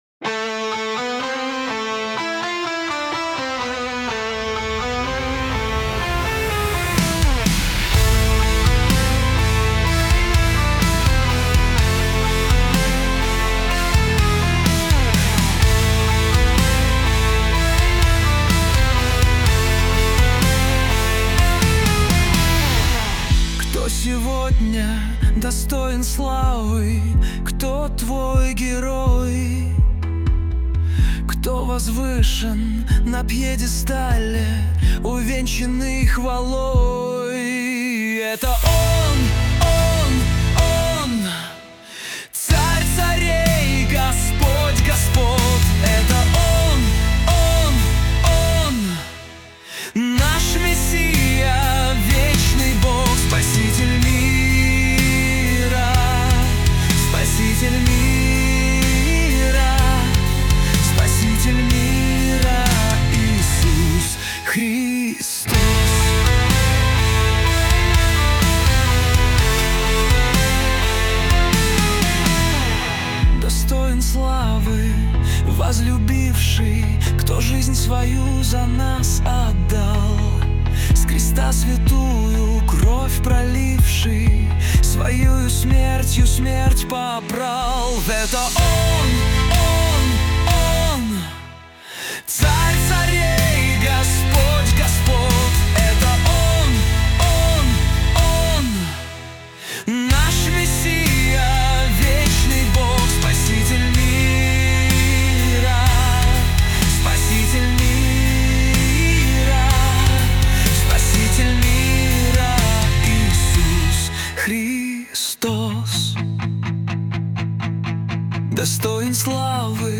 песня ai
185 просмотров 764 прослушивания 48 скачиваний BPM: 125